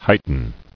[height·en]